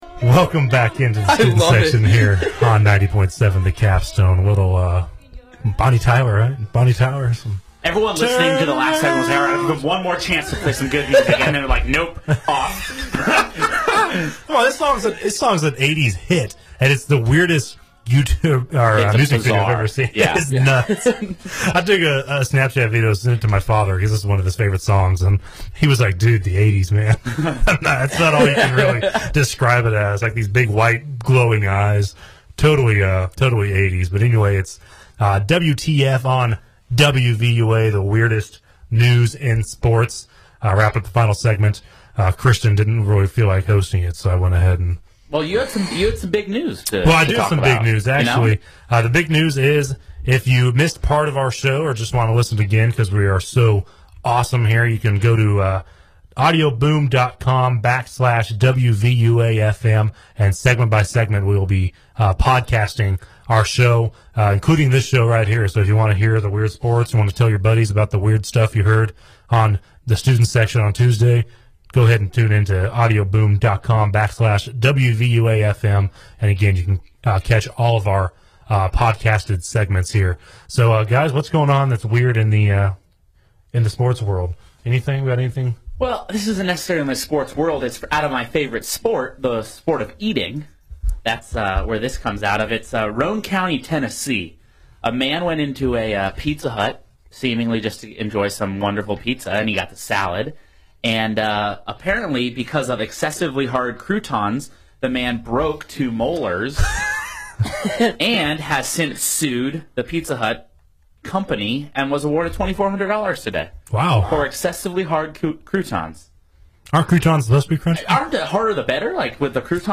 WVUA-FM's flagship sports talk show: The Student Section